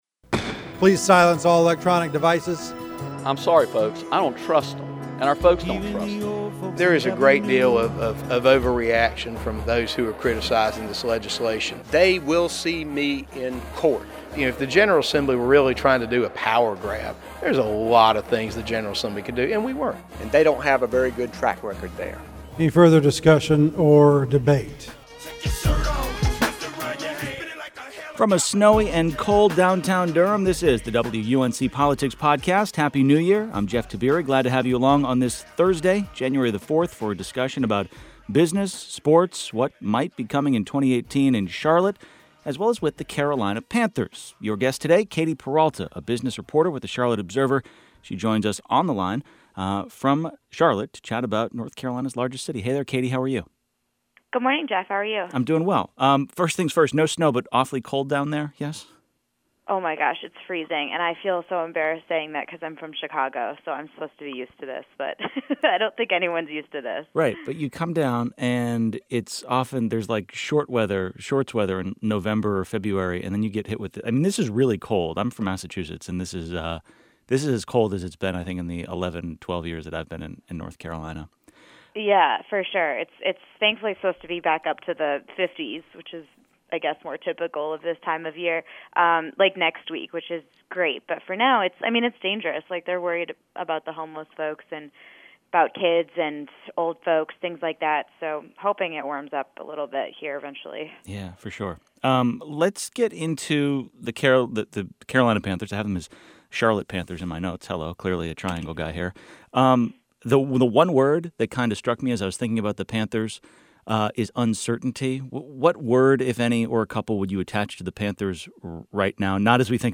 WUNCPolitics Podcast: A Conversation